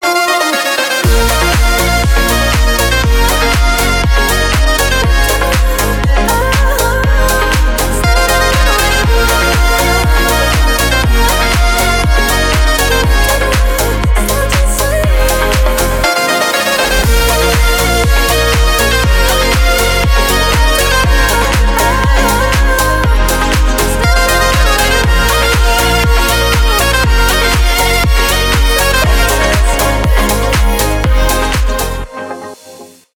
electronic
зажигательные , dance pop